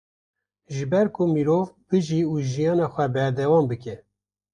Pronounced as (IPA) /bɪˈʒiː/